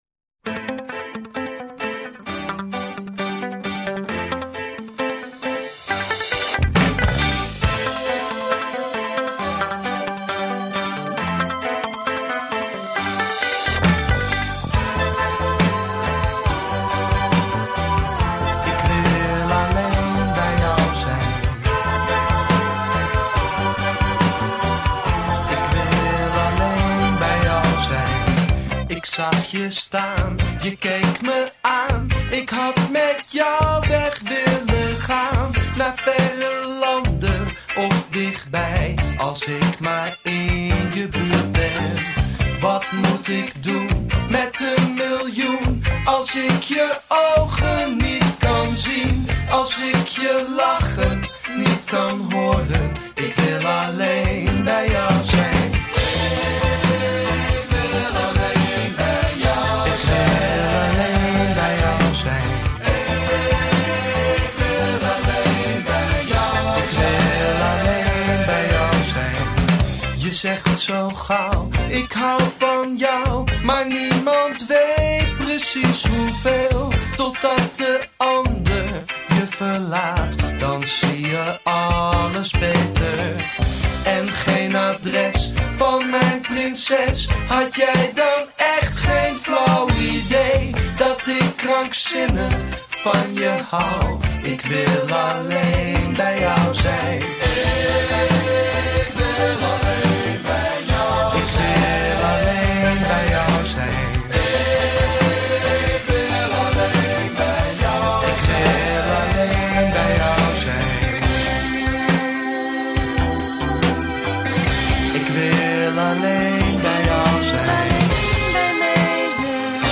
nederpop2.mp3